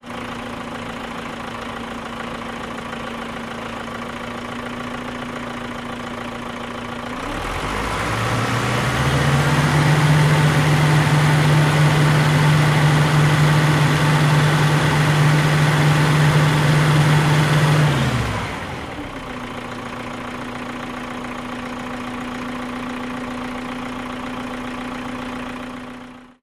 tr_dieseltruck_idle_04_hpx
Diesel truck idles and revs. Vehicles, Truck Idle, Truck Engine, Motor